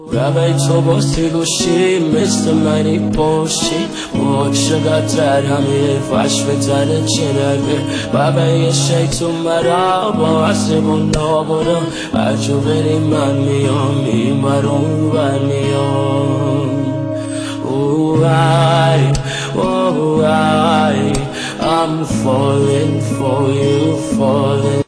ورژن خارجی ریمیکس هوش مصنوعی
ورژن خارجی غمگین شده
با هوش مصنوعی